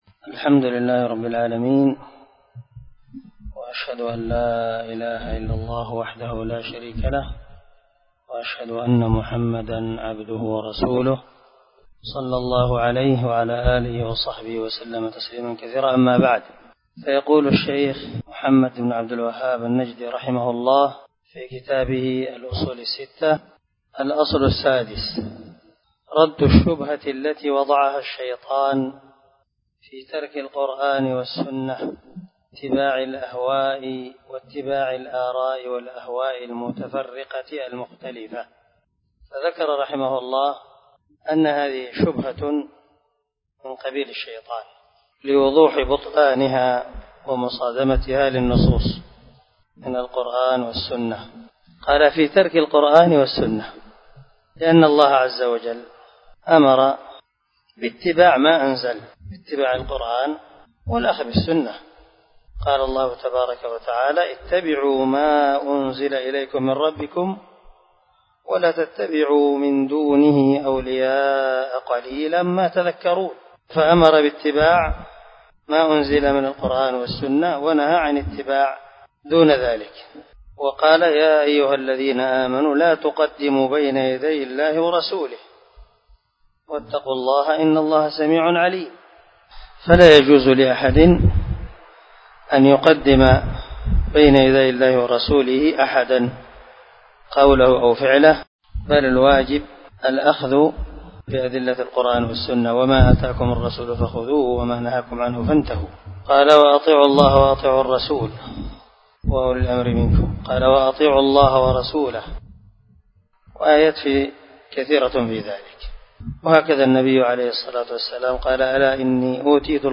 🔊 الدرس 11 من شرح الأصول الستة ( الأصل السادس)
الدرس-11-الأصل-السادس.mp3